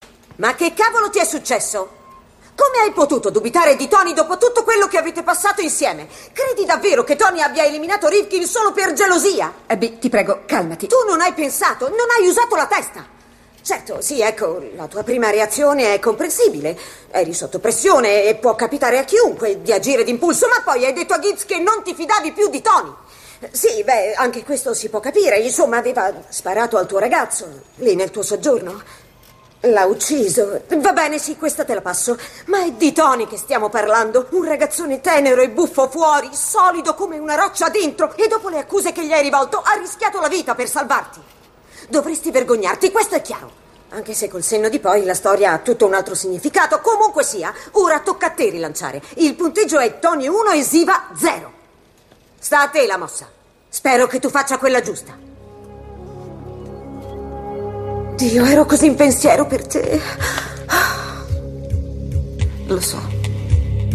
telefilm "NCIS - Unità anticrimine", in cui doppia Pauley Perrette.